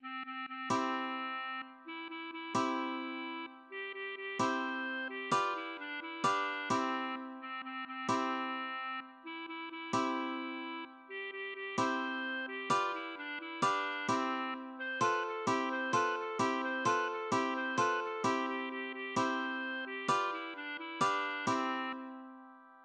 (gesungen anlässlich des Bardenwettbewerbs zur Krönung Großfürsts Alderan von Gareth zu Schloss Auenwacht)